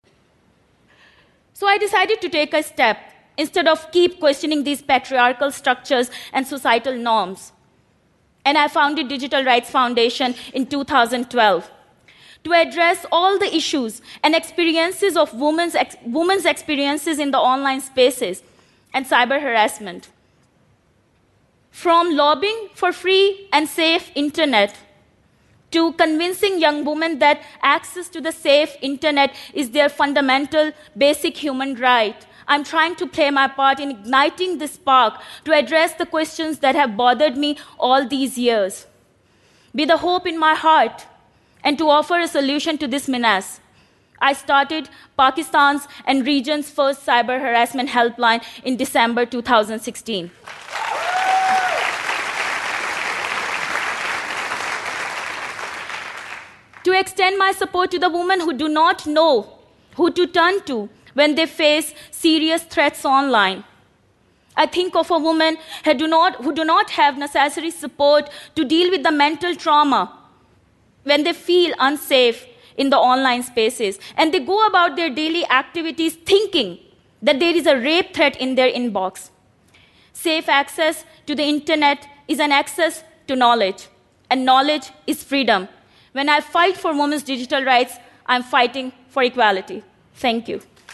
TED演讲